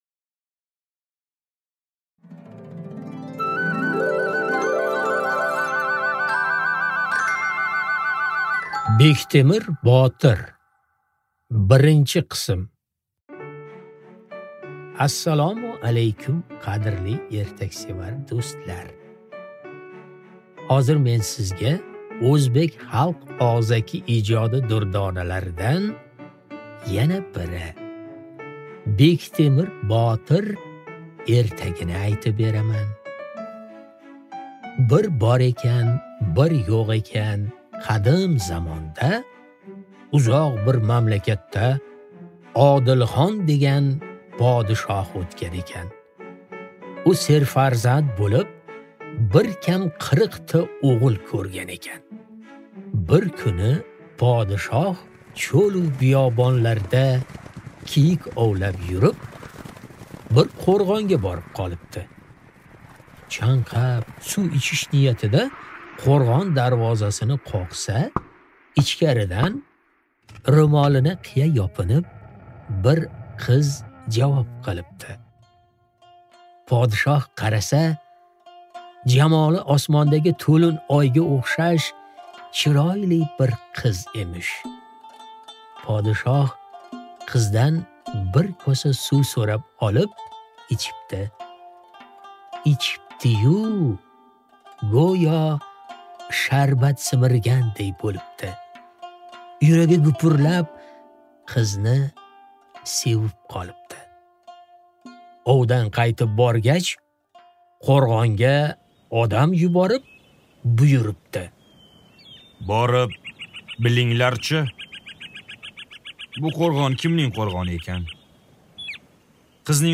Аудиокнига Bektemir botir | Библиотека аудиокниг